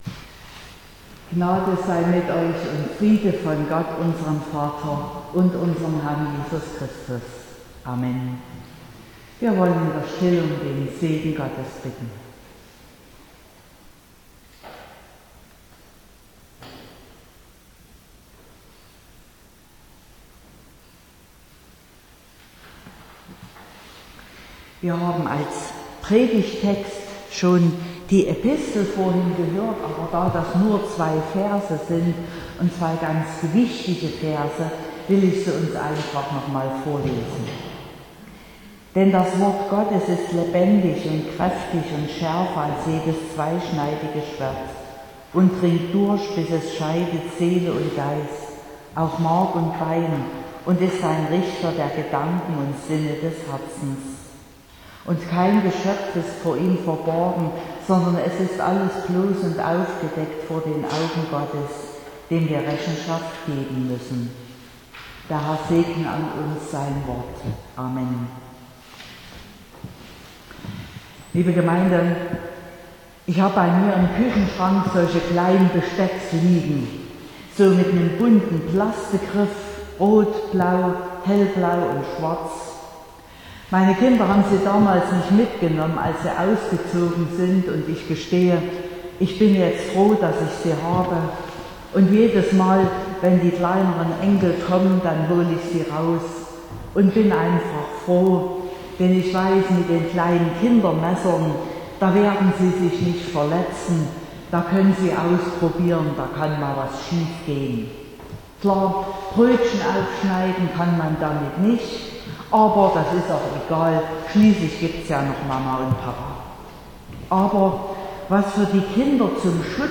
20.02.2022 – Gottesdienst
Predigt (Audio): 2022-02-20_Gottes_Wort_-_Reichtum_und_zweischneidiges_Schwert.mp3 (31,8 MB)